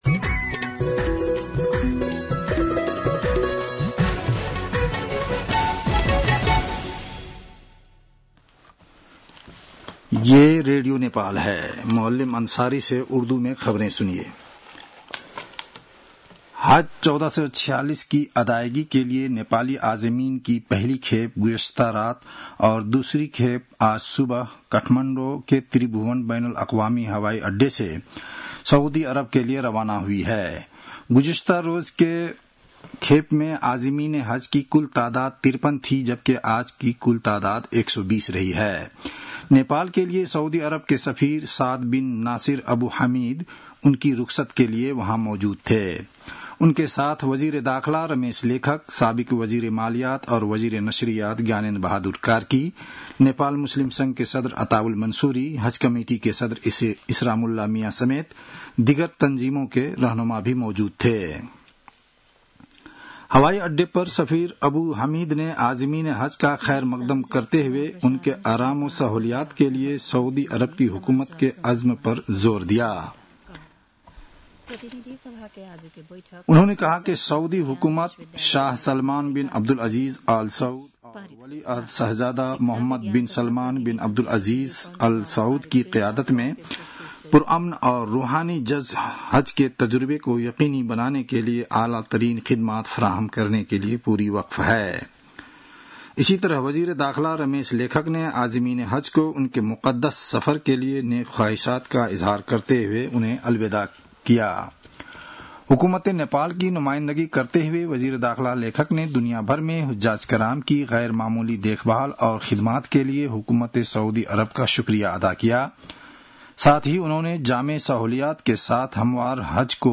उर्दु भाषामा समाचार : ४ जेठ , २०८२